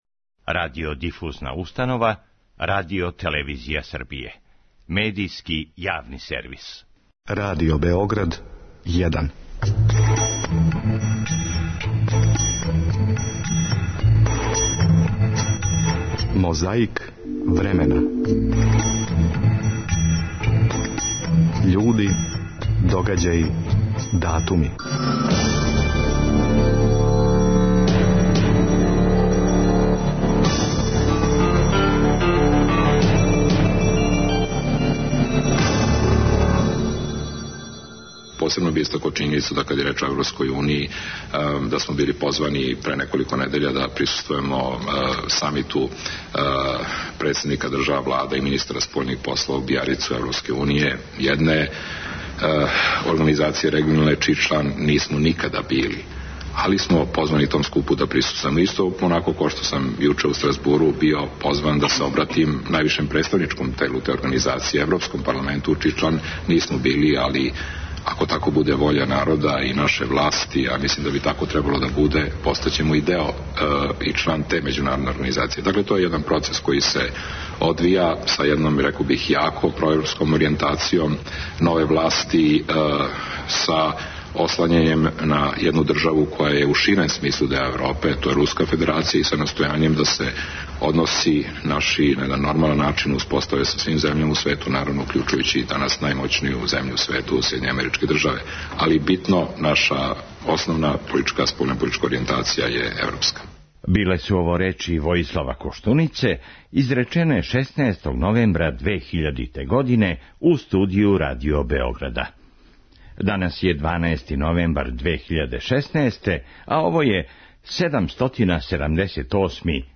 Песмом и причом, па опет песмом, на таласима Радио Београда, представила се странка Демократске акције.
Председник Хрватске Стјепан Месић говорио је за Радио телевизију Србије 12. новембра 2000. године.
По повратку из Стразбура, гост Првог програма Радио Београда 16. новембра 2000. био је Војислав Коштуница.
На отварању пруге „Шамац-Сарајево" 16. новембра 1947. године Тито је говорио надахнуто, а срећни су били и омладинци и омладинке.